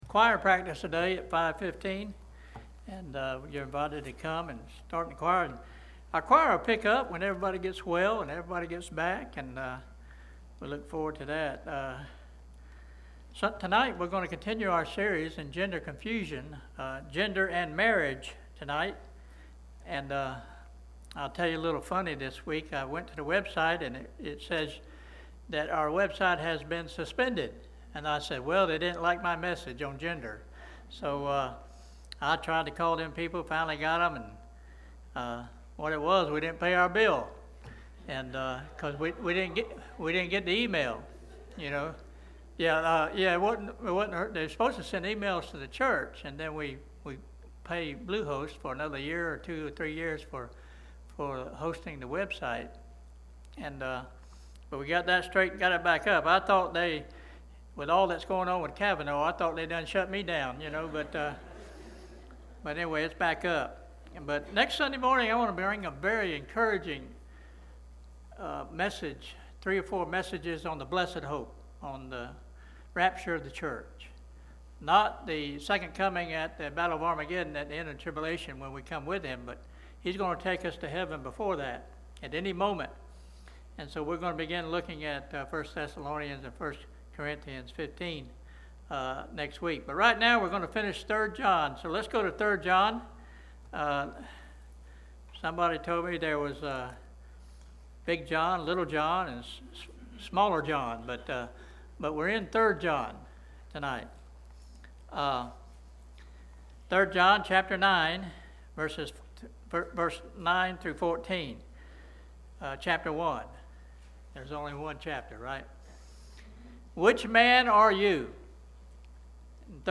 Adult Sunday School
introductory teaching